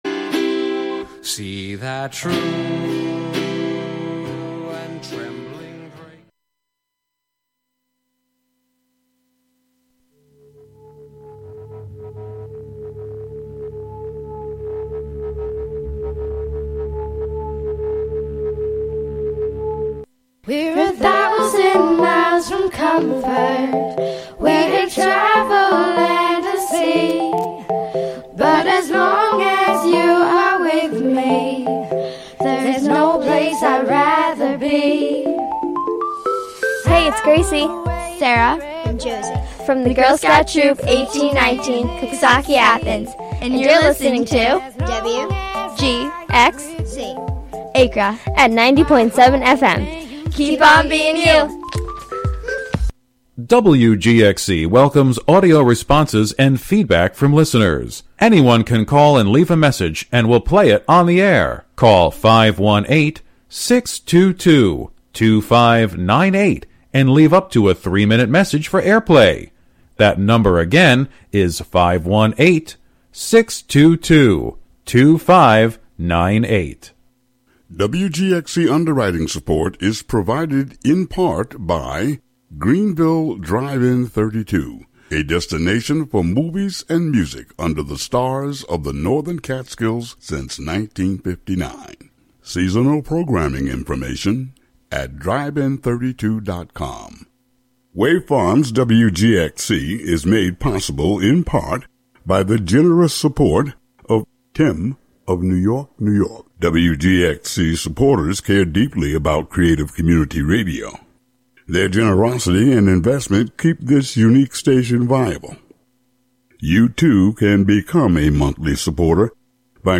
Though the outcome may not have been what many had hoped for, the conversation will center on how to find gratitude in moments of challenge and how resilience can guide us forward. Listeners are encouraged to call in during the Roundtable portion and share, along with our Special guests, what they’re most thankful for and any reflections of how our past may guide us to future progress.